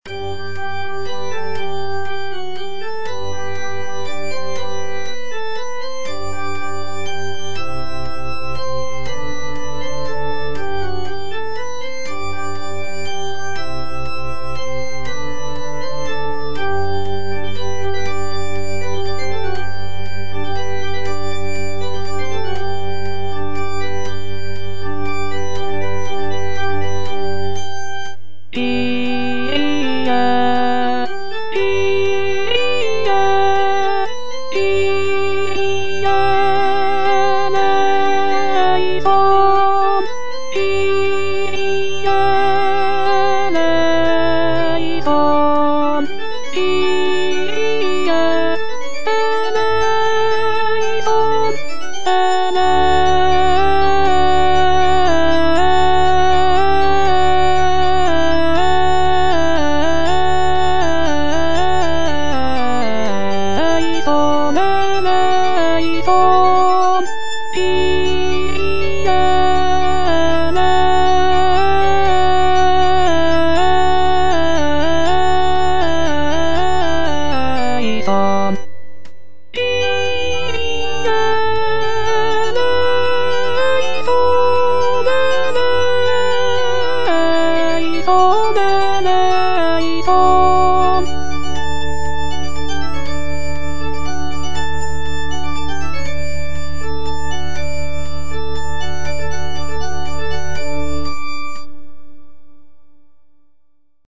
choral work